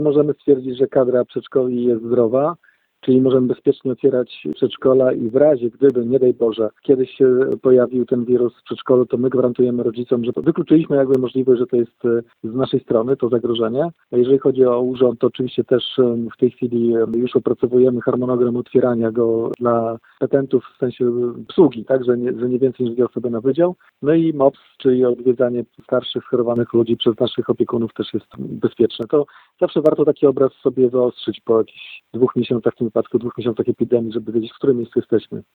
– Mamy dzięki temu pewność, że między innymi kadra przedszkoli jest bez wirusa – mówi Wojciech Karol Iwaszkiewicz, burmistrz Giżycka.